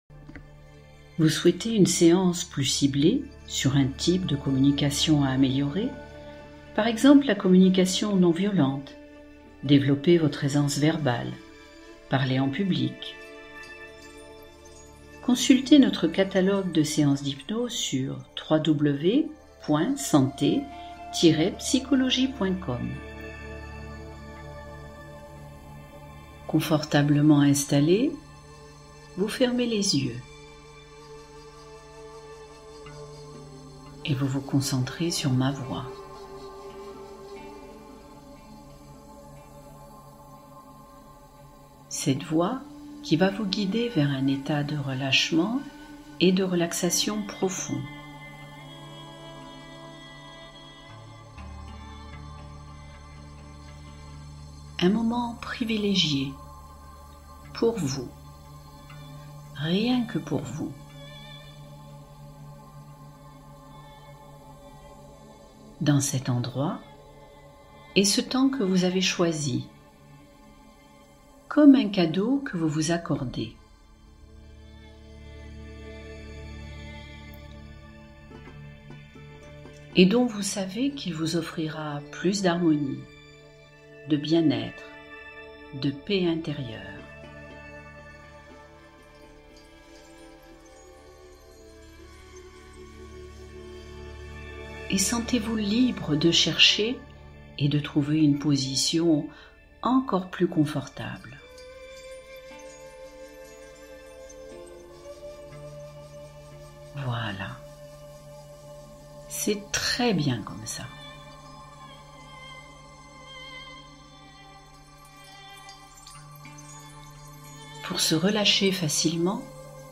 Communication apaisée — Hypnose pour fluidifier les relations